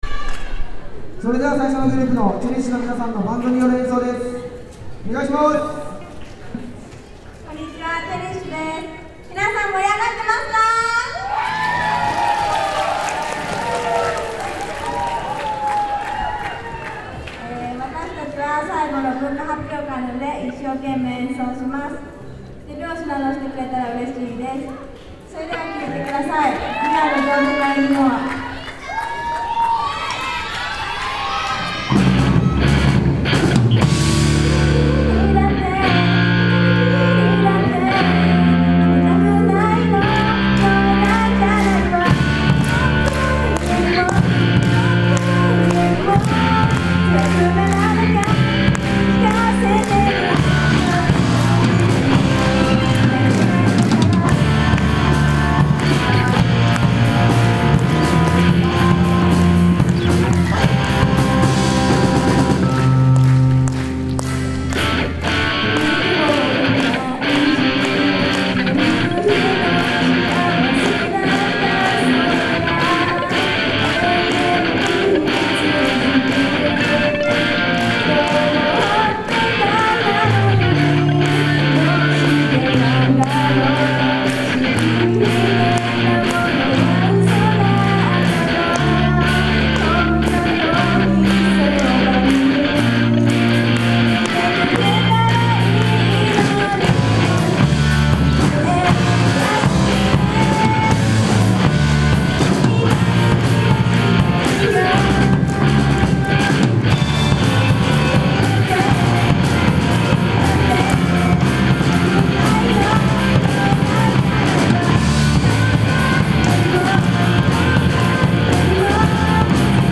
旭祭（あさフェス）♬有志バンド♬
旭祭（あさフェス）での、有志バンド『チェリッシュ』の演奏と写真をアップしました。
有志バンド.mp3